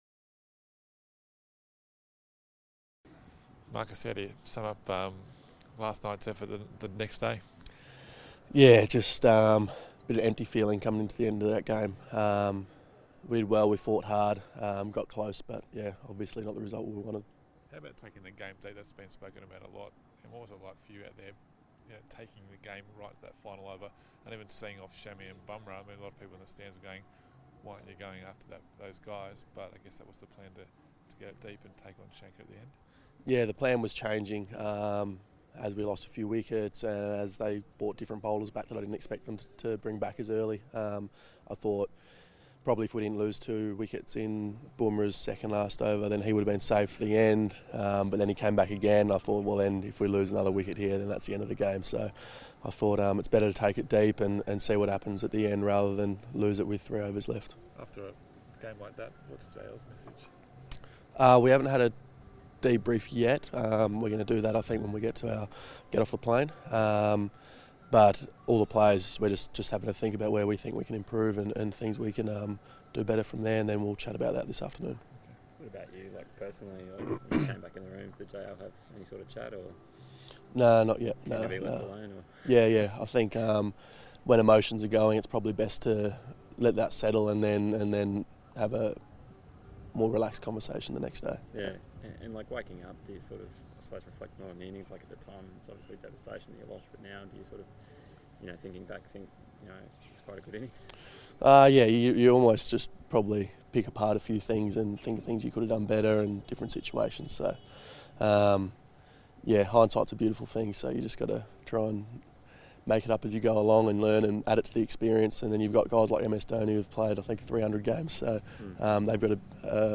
Marcus Stoinis Audio speaks to the media ahead of the third ODI against India